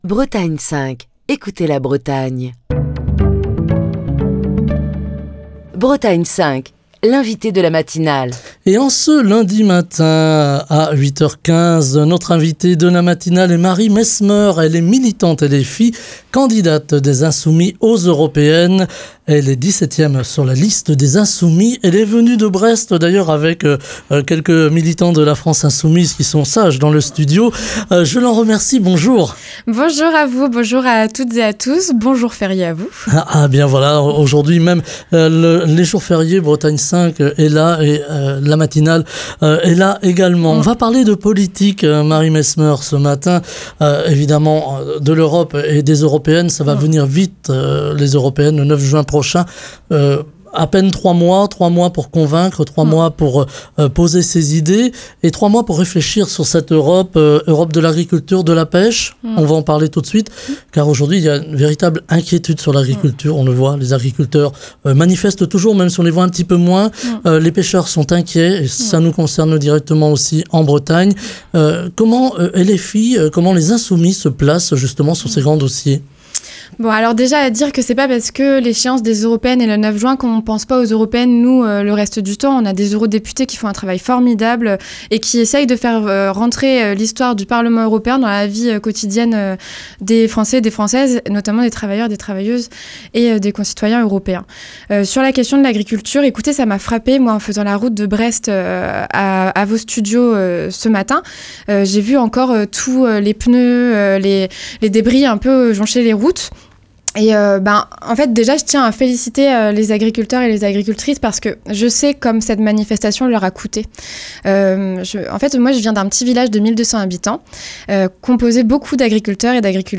Ce lundi, Marie Mesmeur, militante LFI, candidate La France insoumise pour les élections européennes, invitée politique de la matinale de Bretagne 5, détaille les grandes lignes du programme pour les européennes de la France Insoumise. L'agriculture, la pêche, la souveraineté alimentaire, les défis de l'Europe face au changement climatique, la construction de l'Europe sociale, ou encore le fonctionnement de l'Union européenne, sont autant de sujets que les Insoumis entendent défendre lors de cette campagne pour les Européennes.